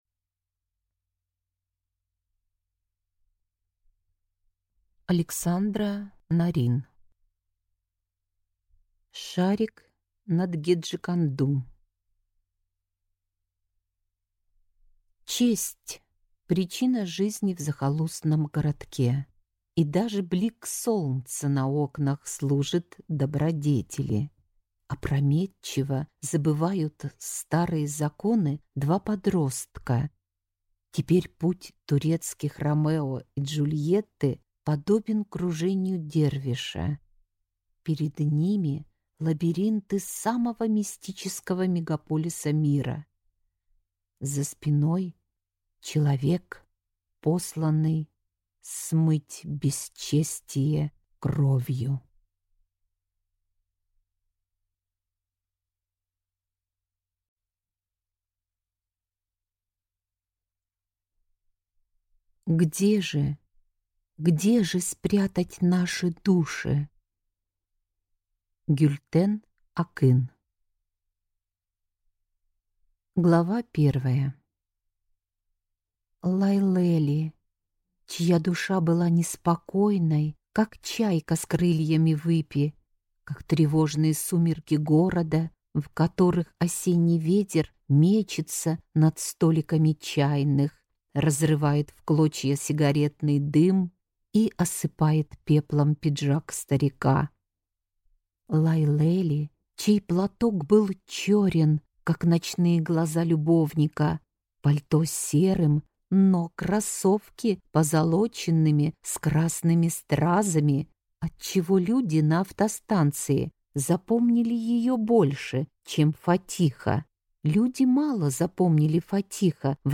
Аудиокнига Шарик над геджеконду | Библиотека аудиокниг